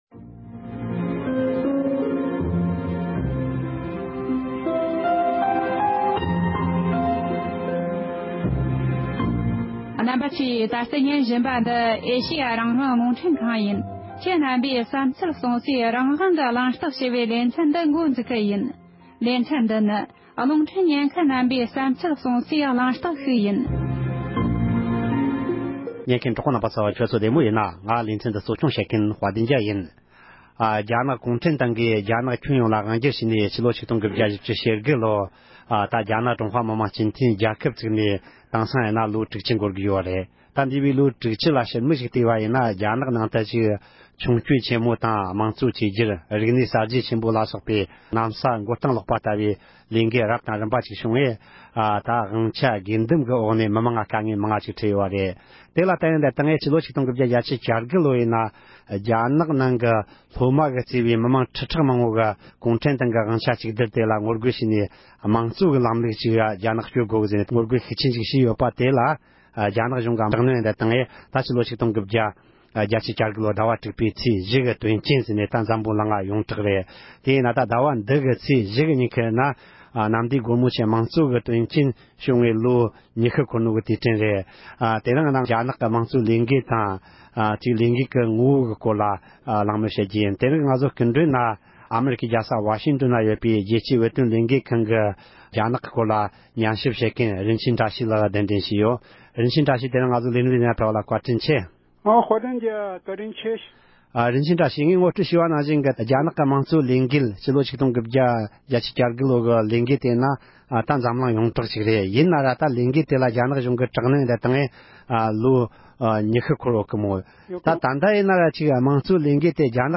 དྲུག་བཞིའི་དོན་རྐྱེན་དང་འབྲེལ་བའི་གླེང་མོལ།